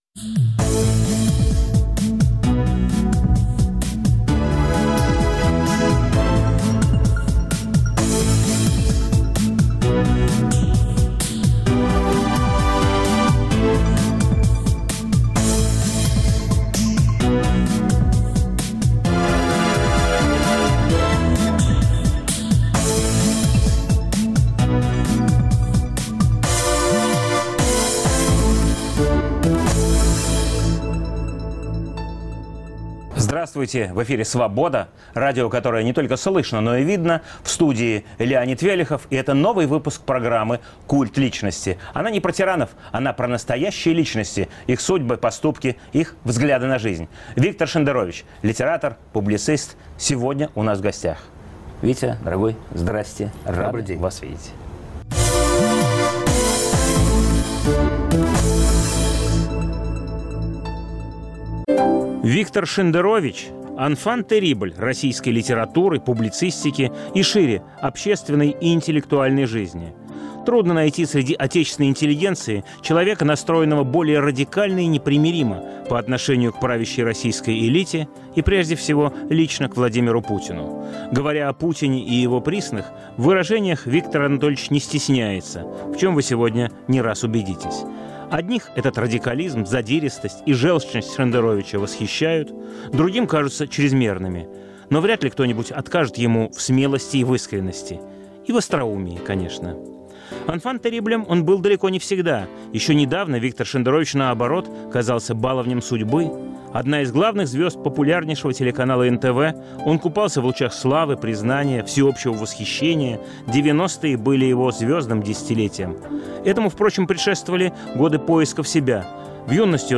Программа о настоящих личностях, их судьбах, поступках и взглядах на жизнь. В студии писатель и публицист Виктор Шендерович.